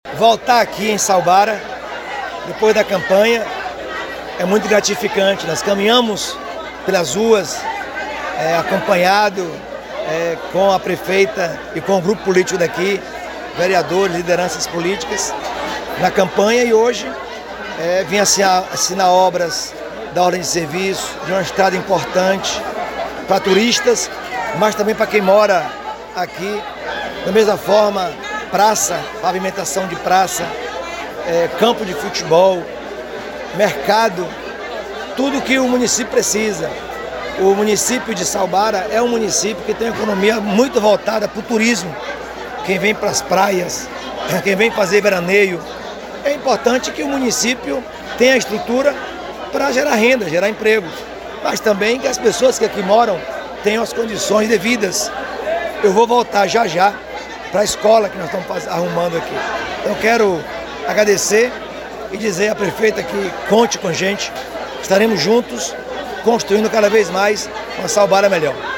Sonora Governador Jerônimo Rodrigues